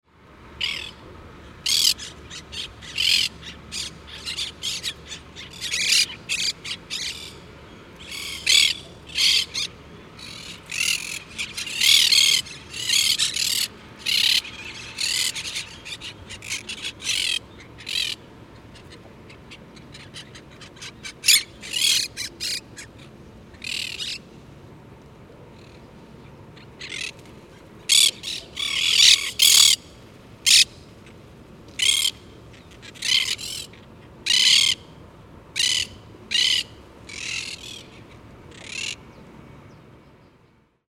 Caturrita
Myiopsitta monachus
É uma espécie bem barulhenta e gosta de fazer ninhos - um emaranhado de gravetos - em palmeiras ou até mesmo postes.
caturrita.mp3